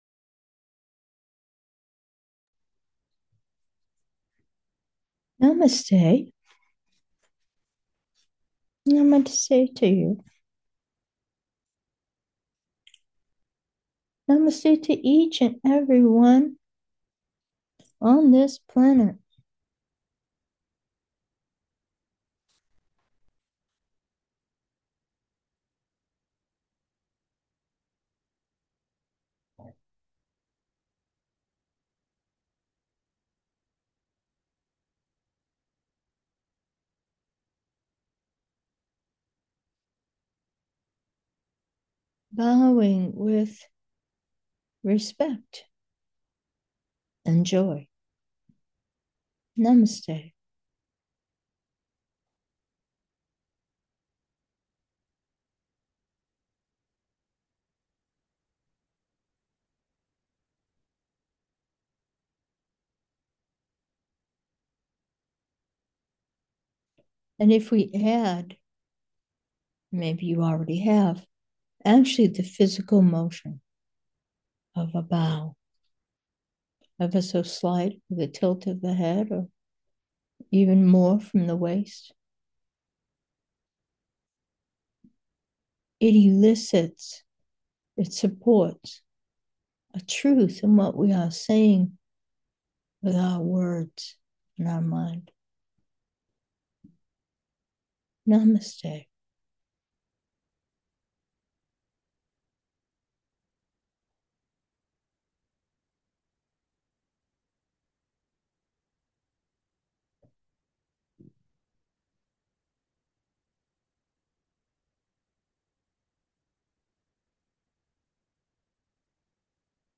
Meditation: breath 11, humanity reflected in me